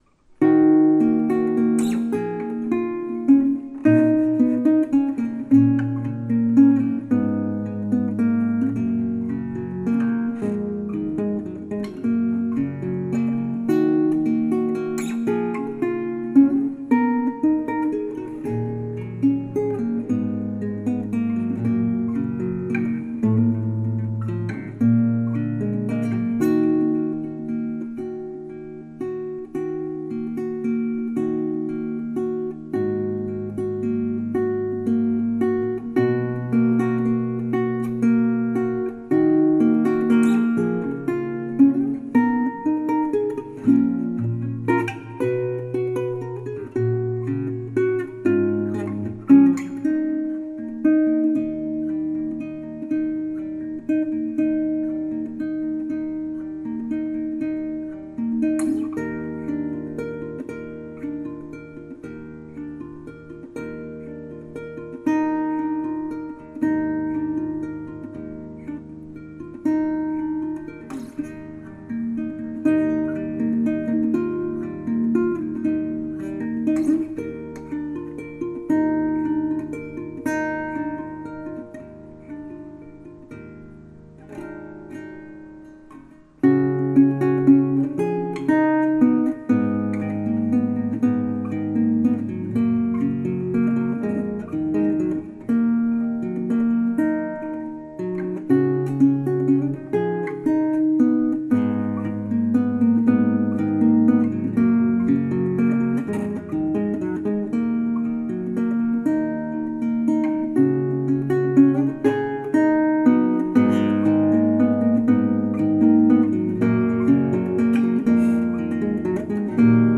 タグ: アコースティック、ギター、ロマンチック、バレンタイン